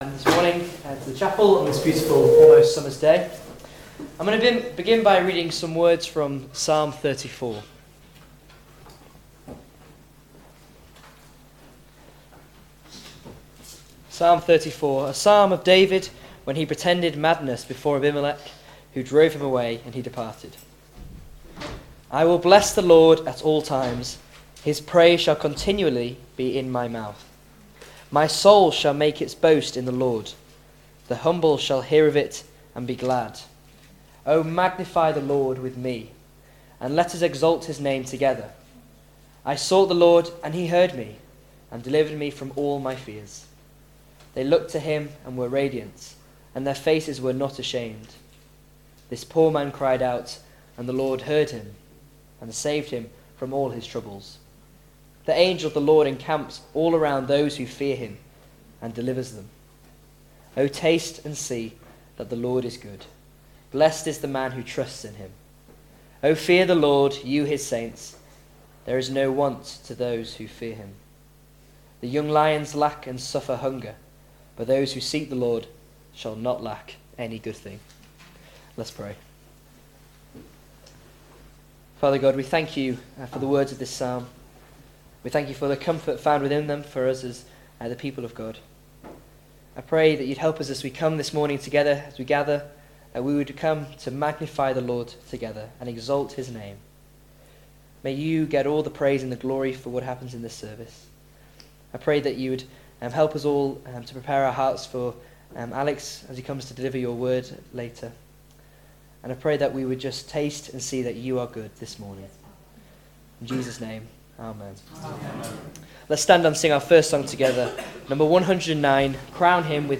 Below is audio of the full service.